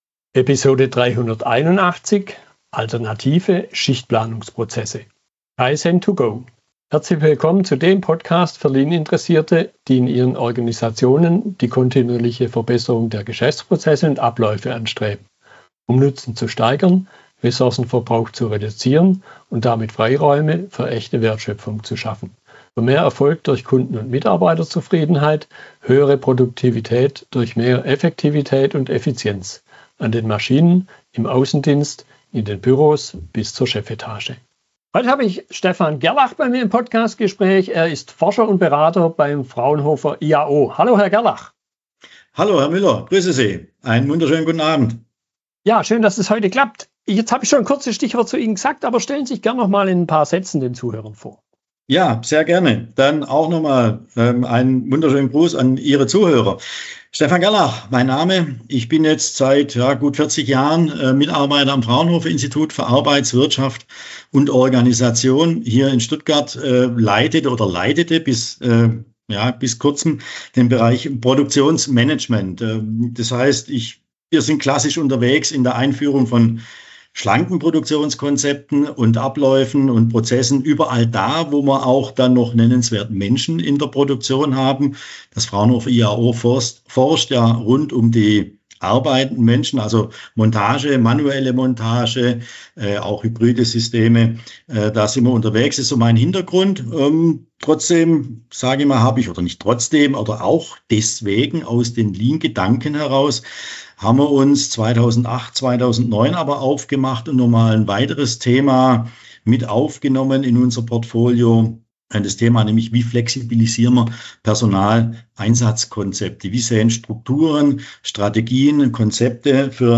Fragestellungen aus der Unterhaltung